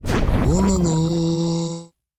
Minecraft Version Minecraft Version snapshot Latest Release | Latest Snapshot snapshot / assets / minecraft / sounds / mob / evocation_illager / prepare_wololo.ogg Compare With Compare With Latest Release | Latest Snapshot